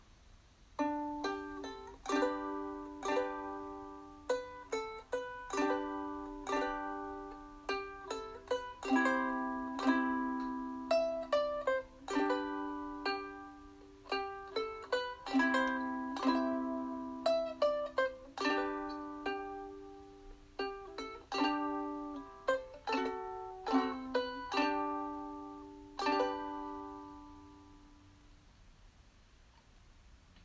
ウクレレの「ポロローン」というなんとも言えない優しい音色は、
ウクレレ、ソロ弾き